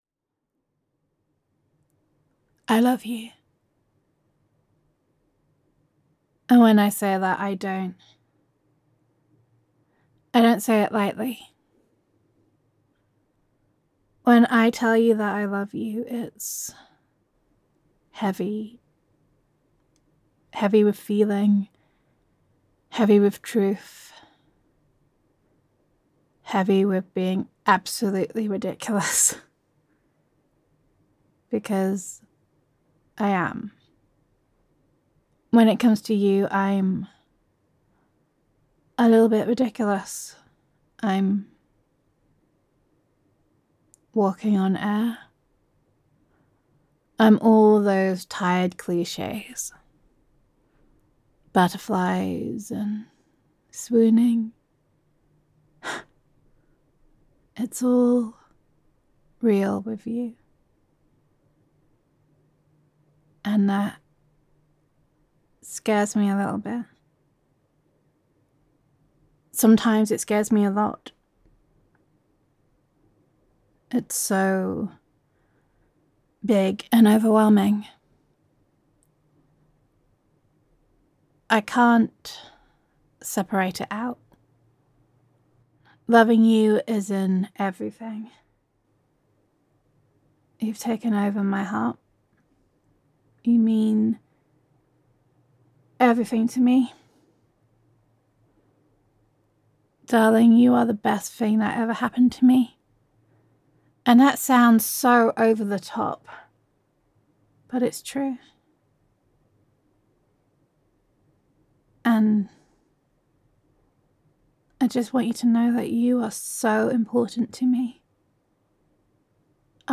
[F4A] A Little Bit Ridiculous [Loving][Adoration][Girlfriend Roleplay][Gender Neutral][Your Girlfriend Adores You]